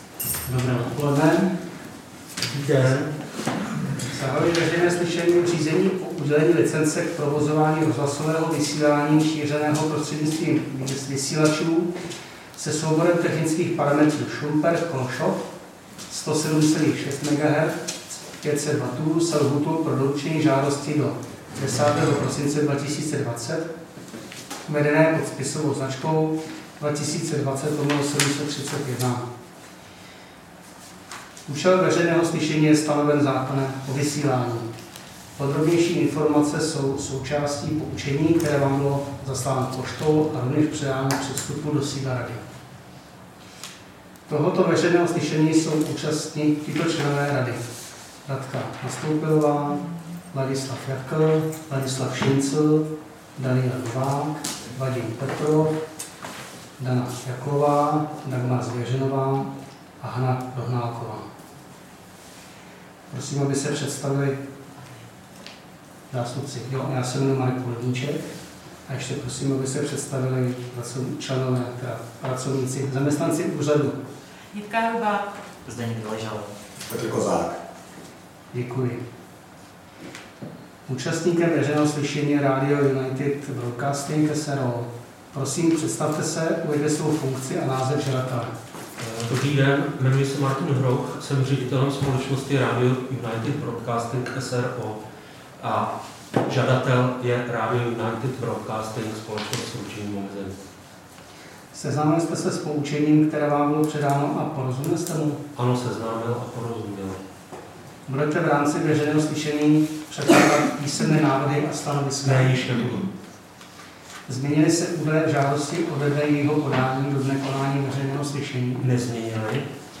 Veřejné slyšení v řízení o udělení licence k provozování rozhlasového vysílání šířeného prostřednictvím vysílačů se souborem technických parametrů Šumperk-Kolšov 107,6 MHz/500 W
Místem konání veřejného slyšení je sídlo Rady pro rozhlasové a televizní vysílání, Škrétova 44/6, PSČ 120 21, Praha 2 Vinohrady.
3. Veřejné slyšení zahajuje, řídí a ukončuje předseda Rady nebo jím pověřený člen Rady.